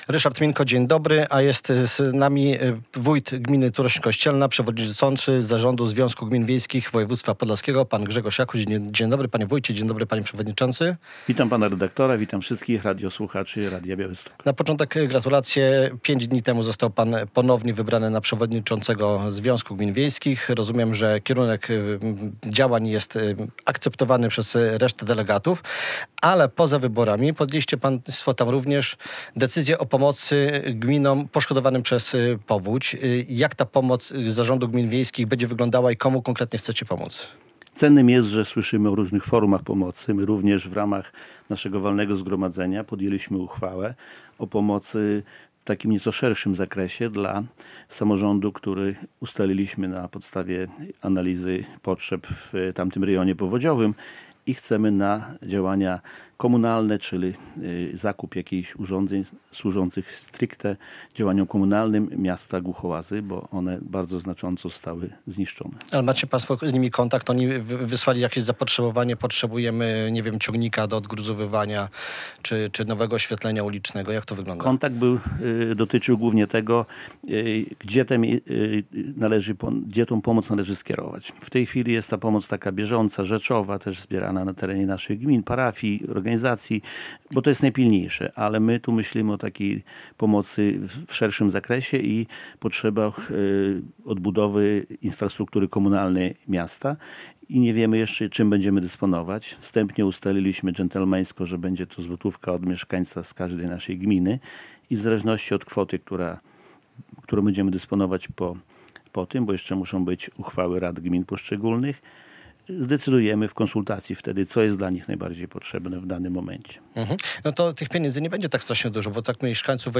Radio Białystok | Gość | Grzegorz Jakuć - przewodniczący Związku Gmin Wiejskich Województwa Podlaskiego, wójt Turośni Kościelnej